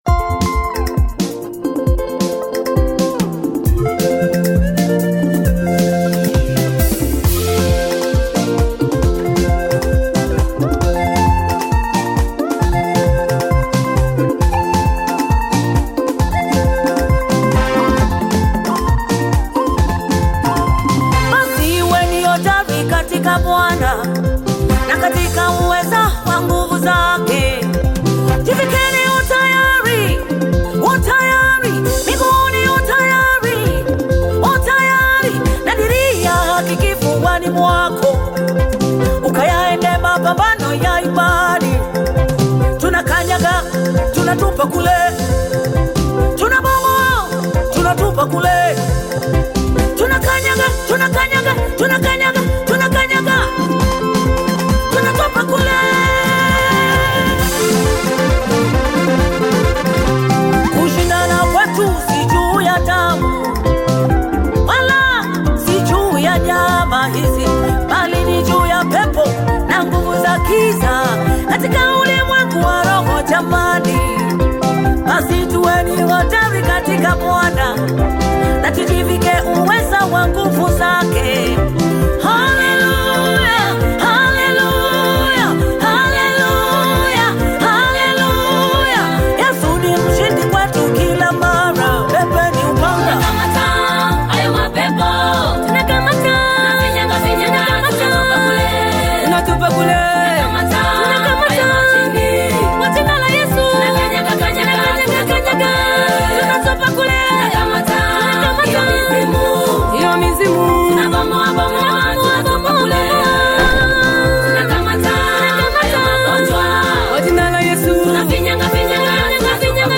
Tanzanian MusicGospel